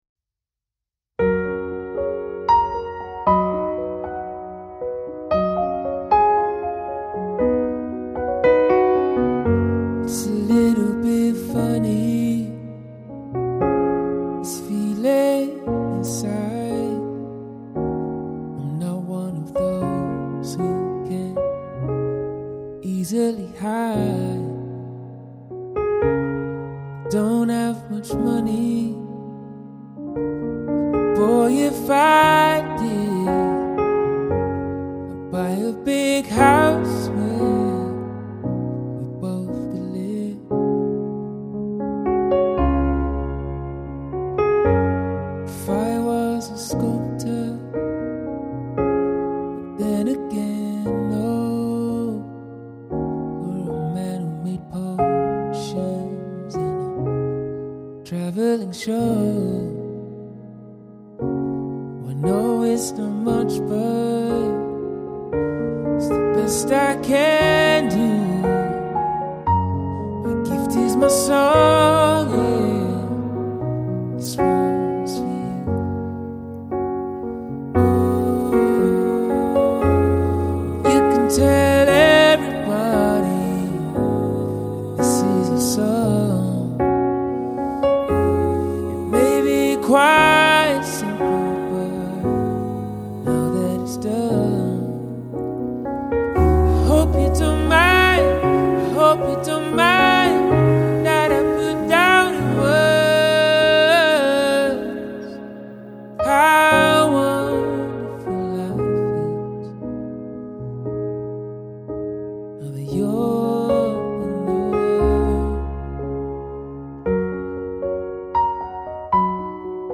Powerful & soulful singer-guitarist.